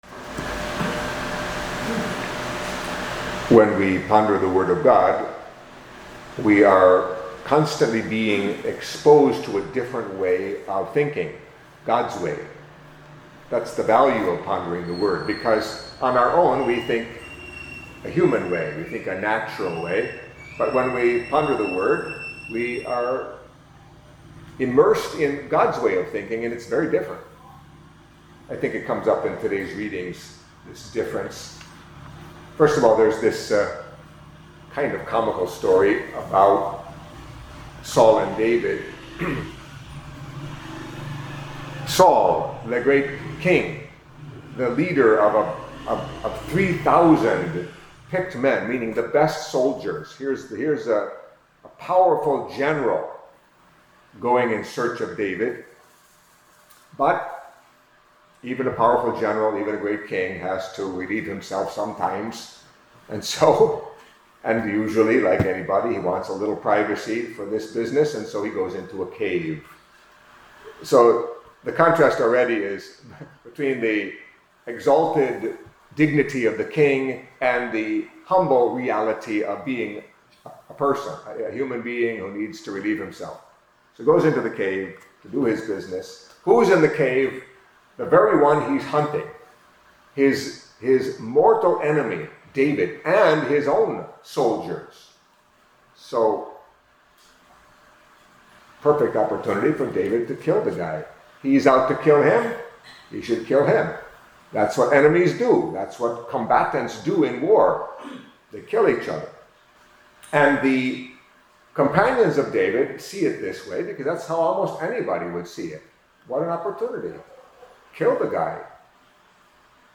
Catholic Mass homily for Friday of the Second Week in Ordinary Time